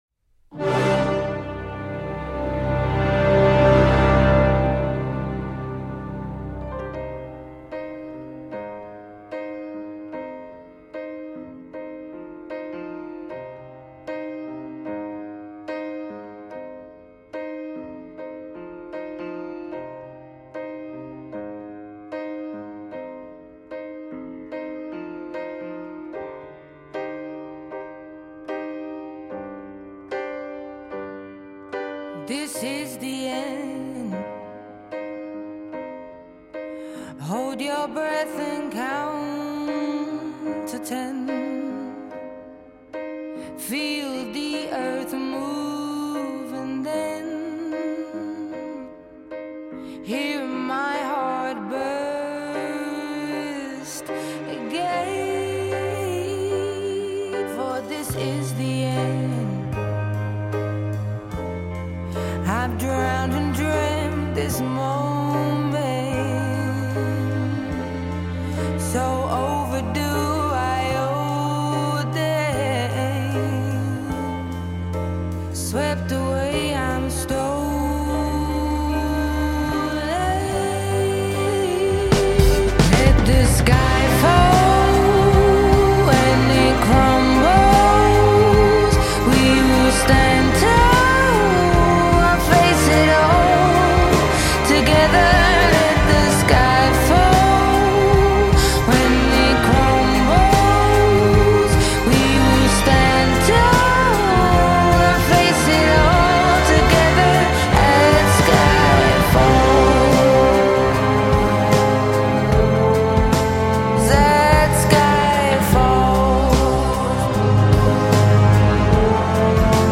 ژانر: پاپ & راک
توضیحات: ده موزیک خارجی احساسی